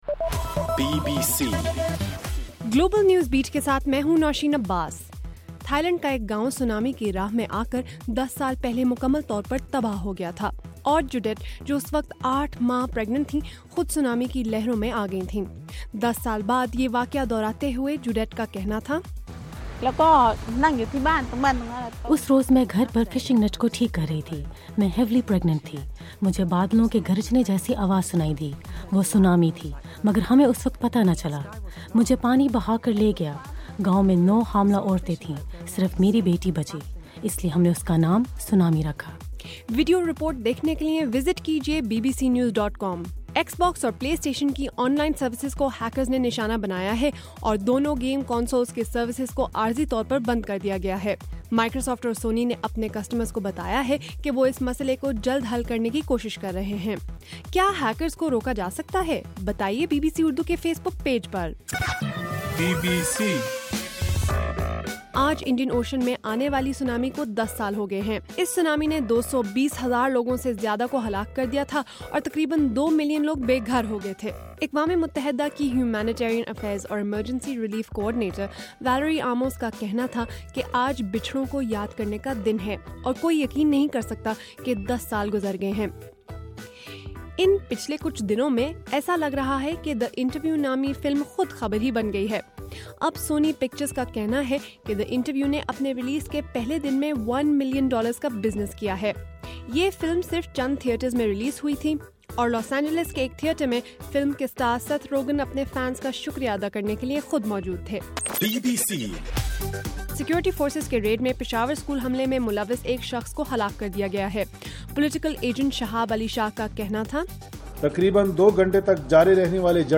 دسمبر 26: رات 12 بجے کا گلوبل نیوز بیٹ بُلیٹن